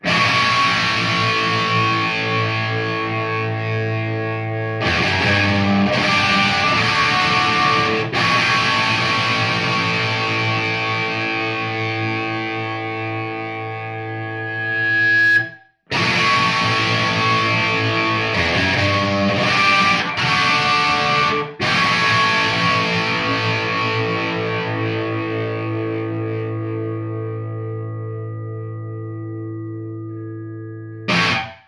Tiens j'ai enregistré quelques samples de mon JVM205H hier, avec ces guitares:
Gibson Les Paul '58 Reissue Historic (WCR Darkburst)
Baffle Rivera monté en 2x Scumback M75 (repiqué en close-miking) et 2xScumback H75
Micros utilisés: Rode 2x NT5 , et Shure SM57
Enregistreur: Zoom H4
J'ai écouté les samples, et ça me fait la même impression que les premiers que tu avais posté : le son est maigre, criard, sans présence...
Les Paul R8 et Marshall JVM205H.mp3